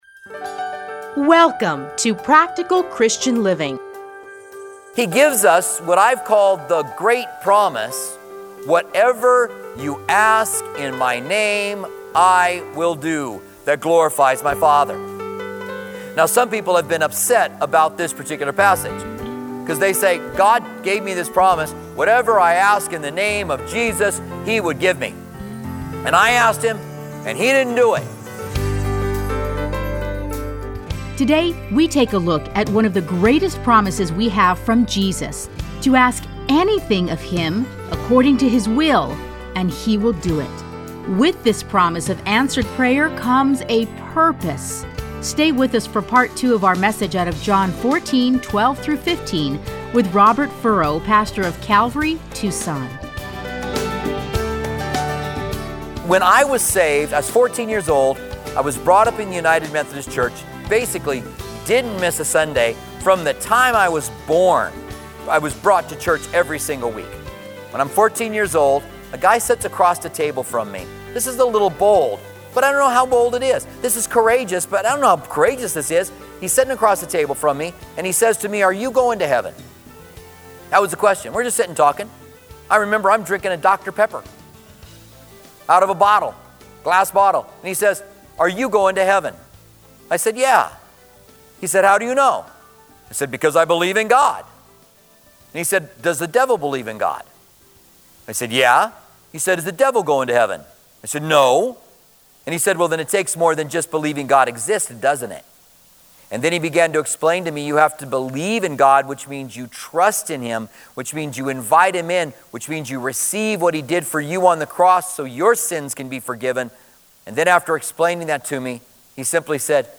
Listen to a teaching from John 14:12-15.